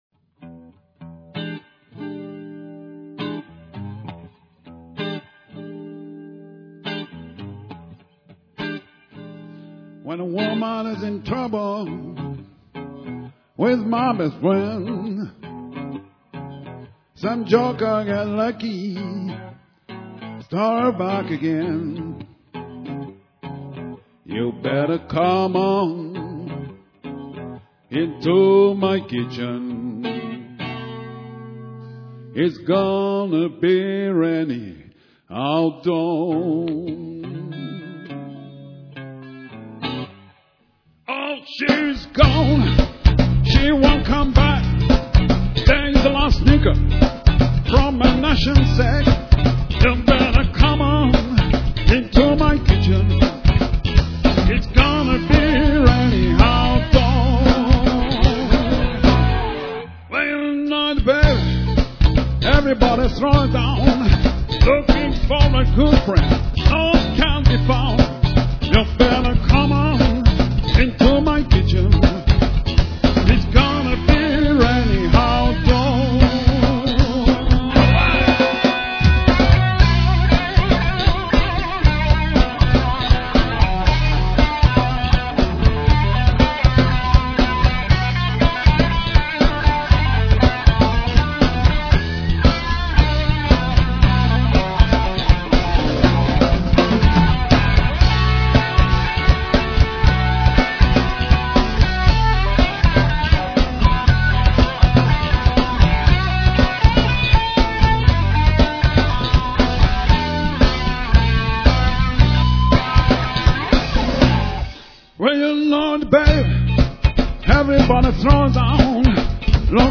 du répertoire Blues.
C'est du mp3, donc un peu compréssé,
d'ou la qualité moyenne du son…